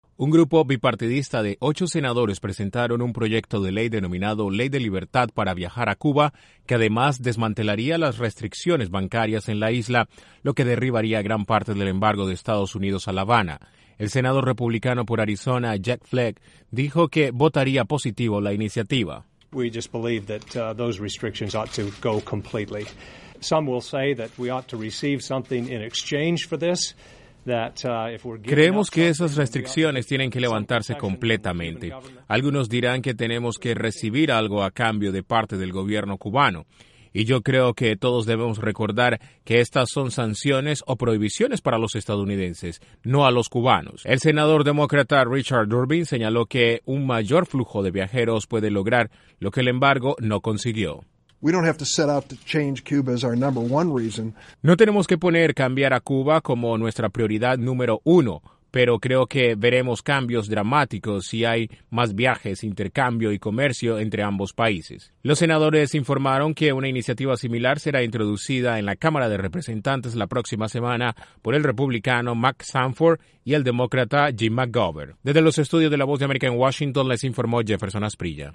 Un grupo bipartidista de senadores estadounidenses presentaron un proyecto ley para levantar la prohibición de viajes a Cuba y las restricciones bancarias en la isla. Desde la Voz de América en Washington informa